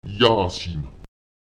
Lautsprecher yásim [Èjaùsim] erwärmen (warm machen)